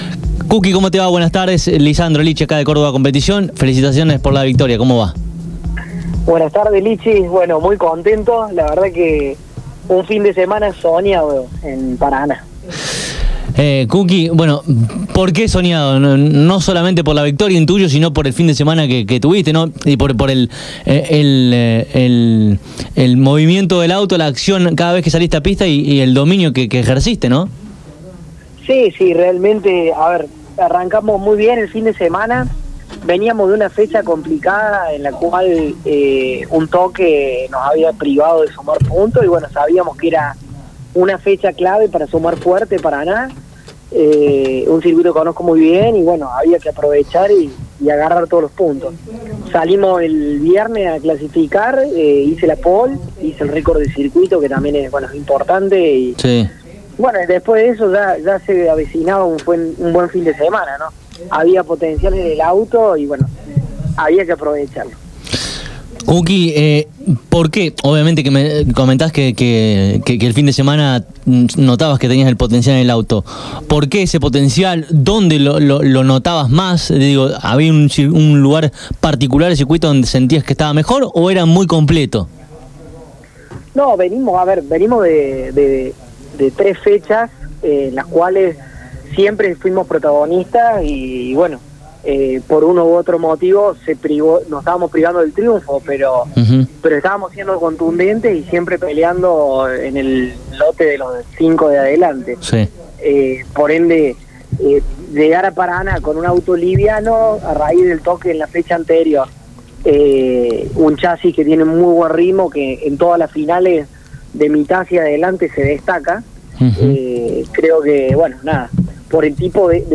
Córdoba Competición mayo 10, 2022 AUDIOS, NACIONALES, OTRAS